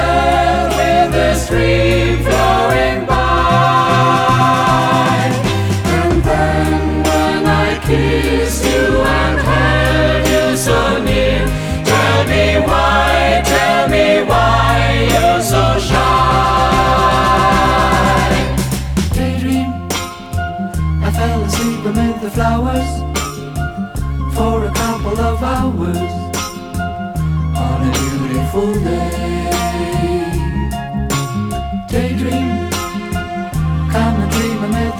# Jazz